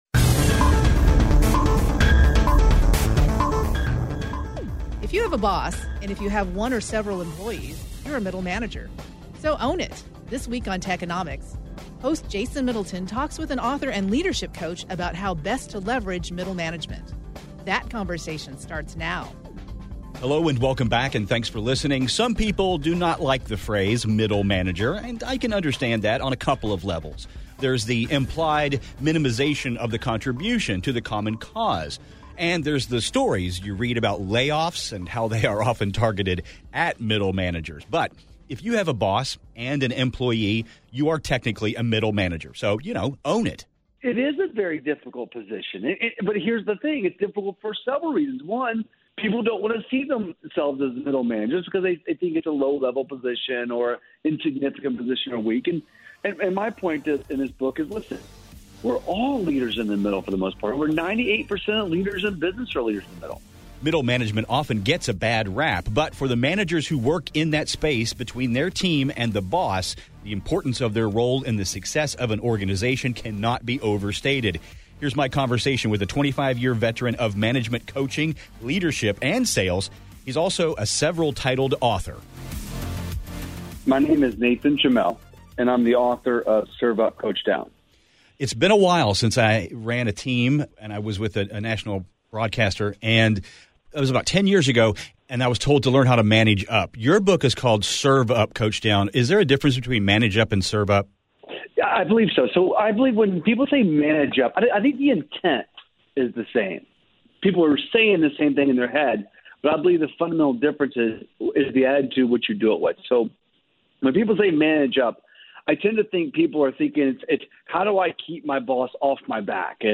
But for the managers who work in that space between their team and the boss, the importance of their role in the success of an organization cannot be overstated. Here’s my conversation with a 25-year veteran of management…